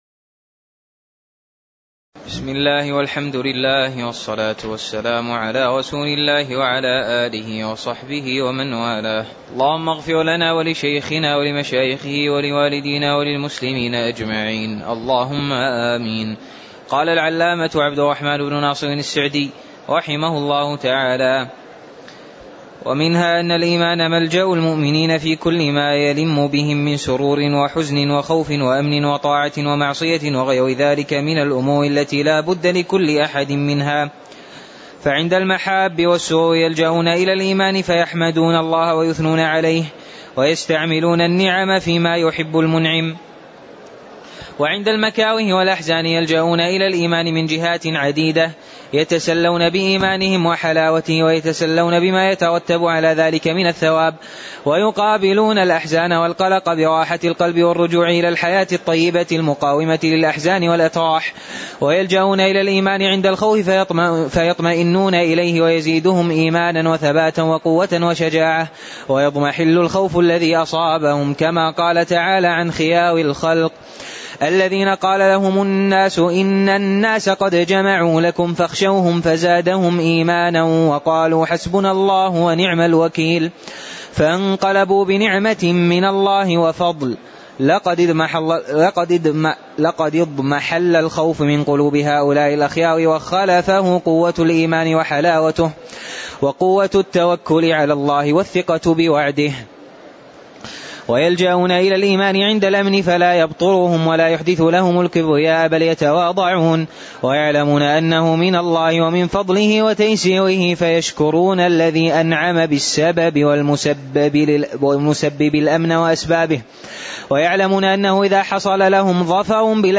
تاريخ النشر ١٣ جمادى الأولى ١٤٤٥ هـ المكان: المسجد النبوي الشيخ